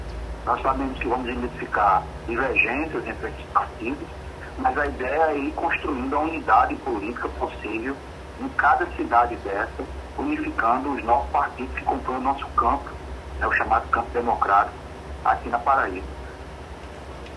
Em entrevista ao programa Arapuan Verdade